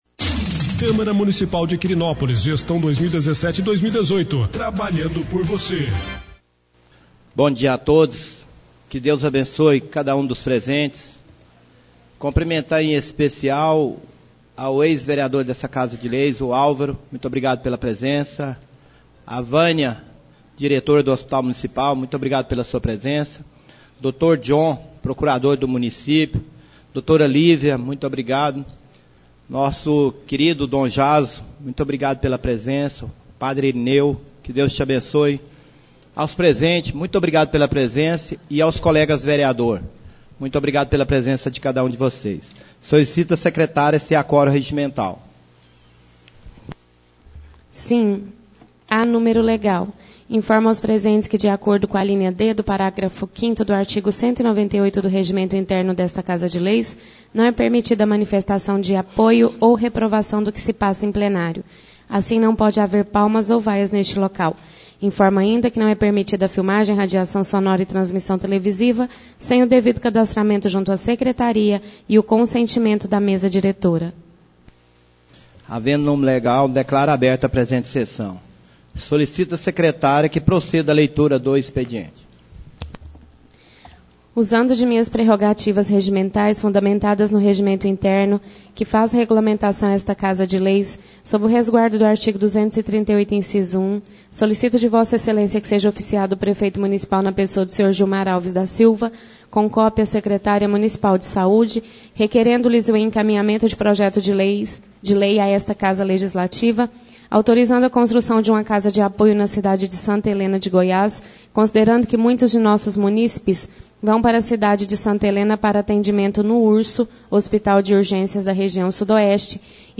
Áudios das Reuniões